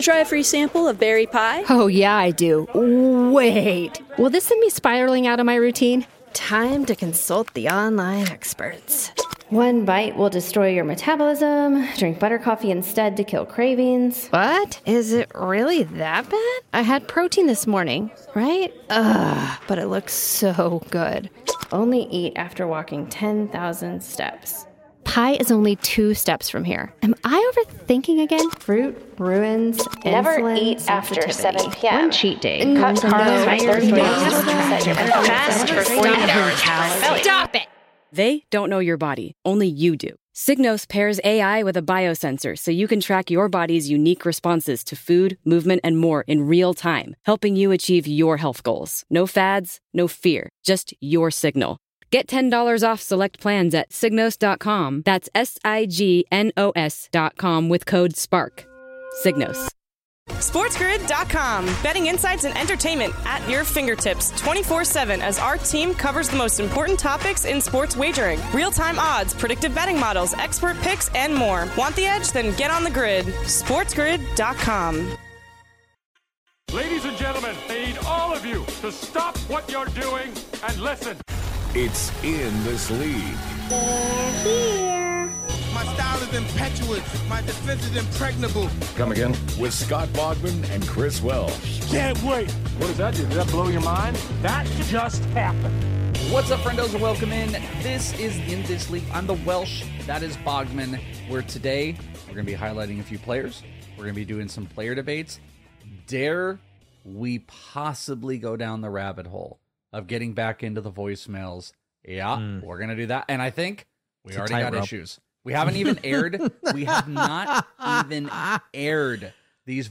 4/16 Hour 1: MLB Player Debates, Listener Voicemails, & More